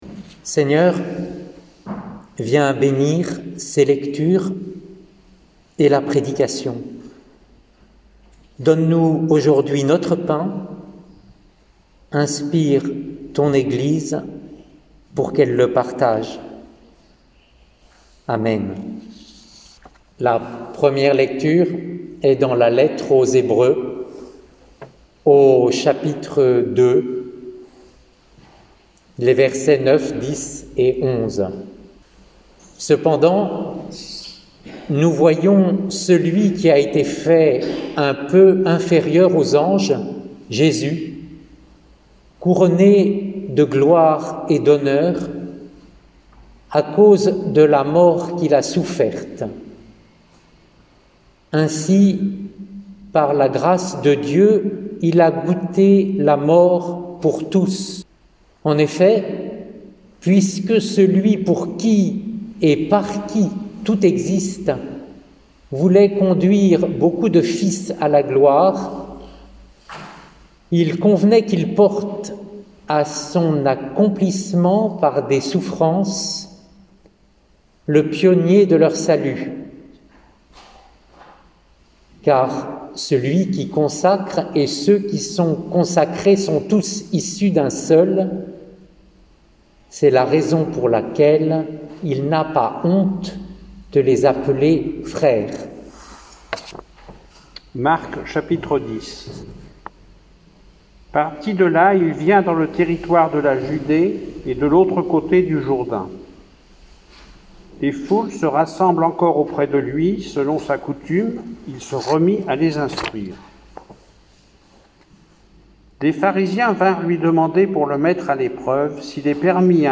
Lectures, prédication, et musiques.mp3 (49.03 Mo)